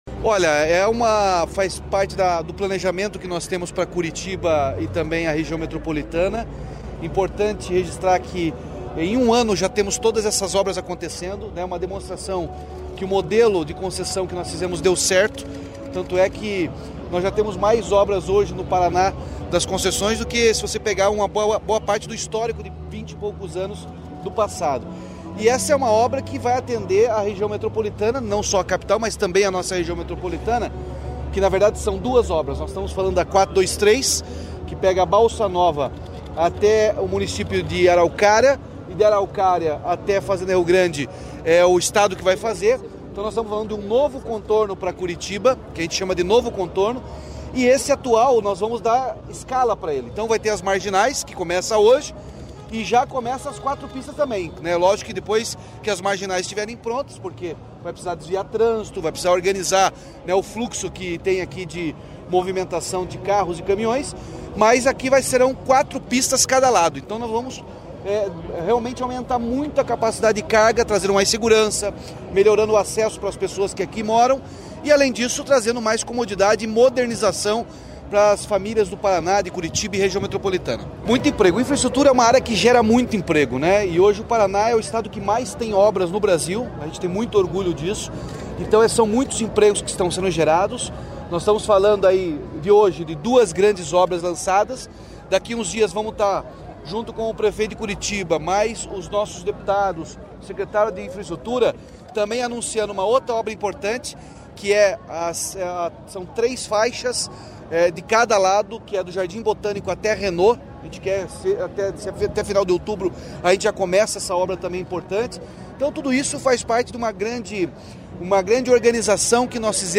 Sonora do governador Ratinho Junior sobre o começo da ampliação do Contorno Sul de Curitiba e da duplicação da PR-423